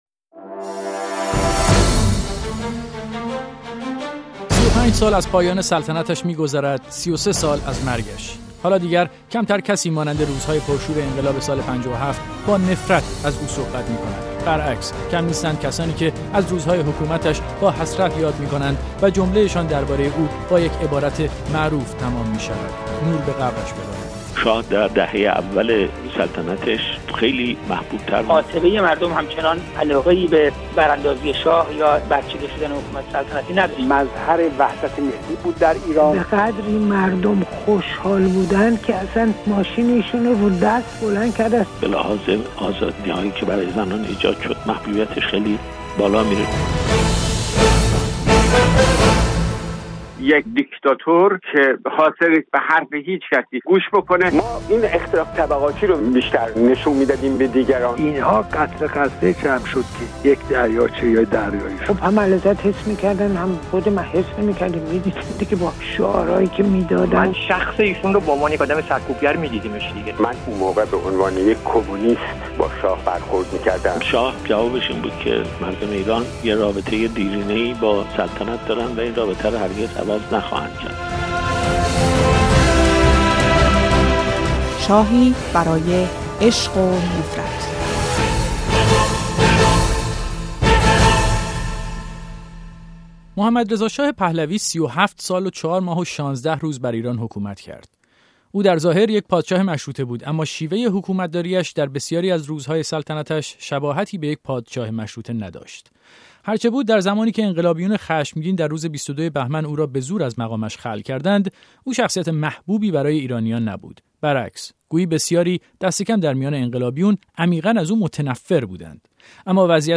مستند رادیویی